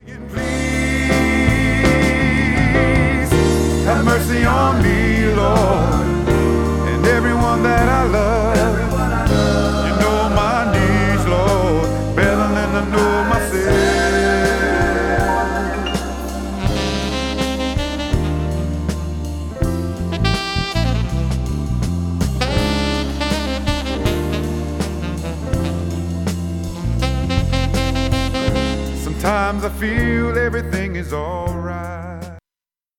the rich voice
lead vocals